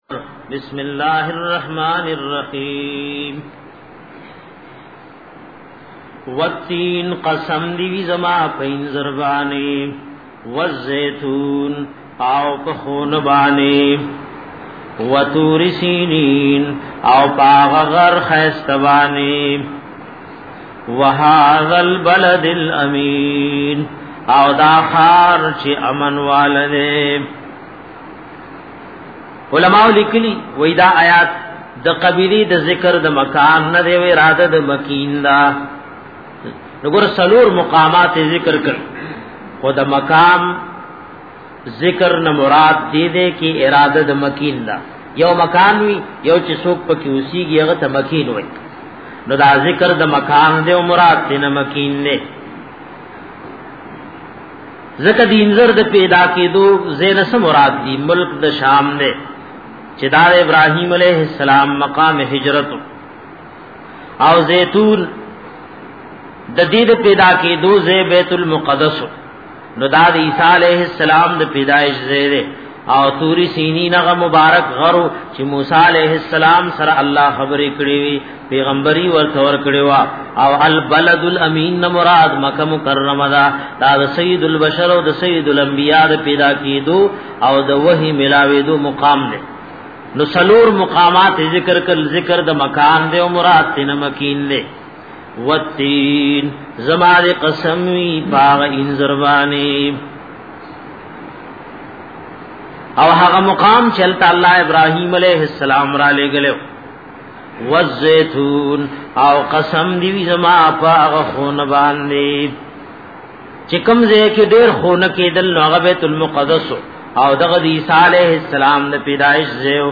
Darse-e-Quran